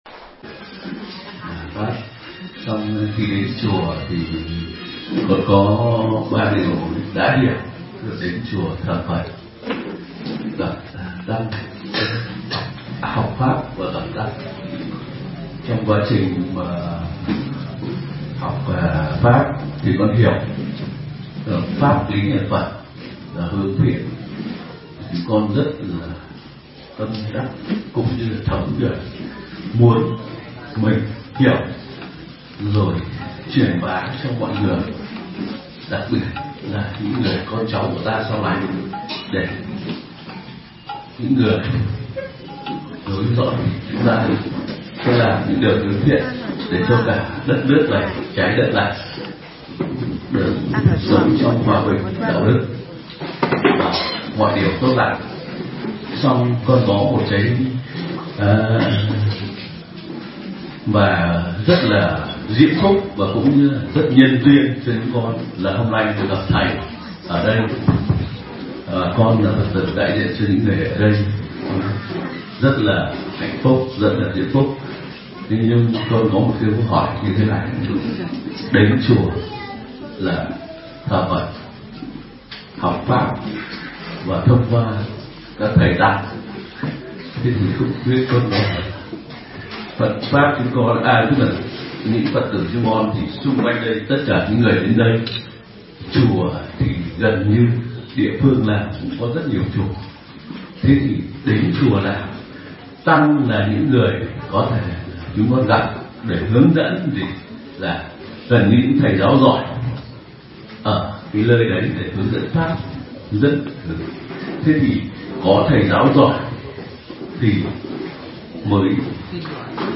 Vấn đáp: Tiêu chi làm tu sĩ – Thầy Thích Nhật Từ Thuyết Giảng